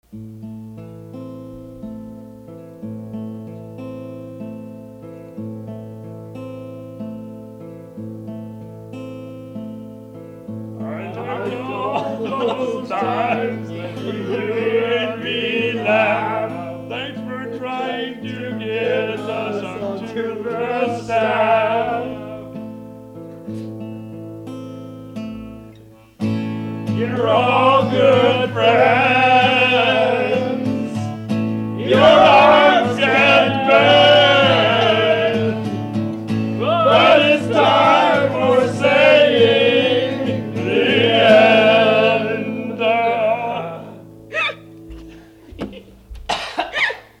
vocals by everyone.